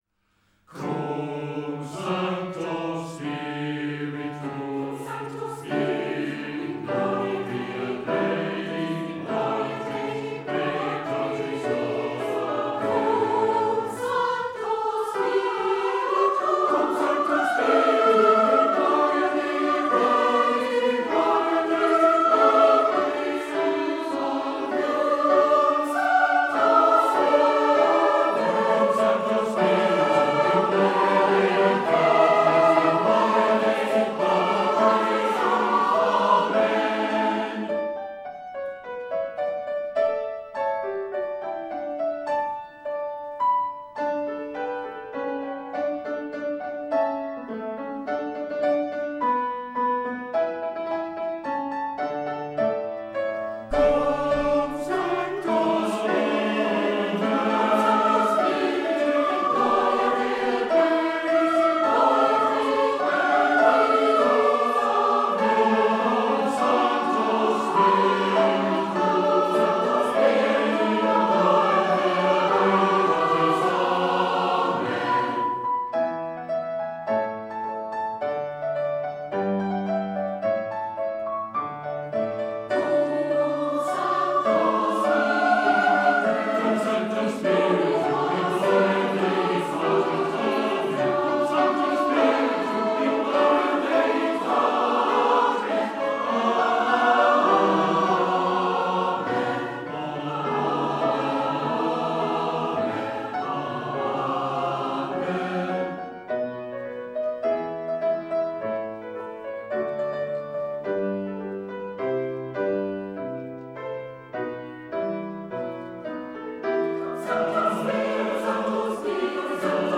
A traditional Welsh lullaby SUO GAN
Recorded by individual members in their homes during lockdown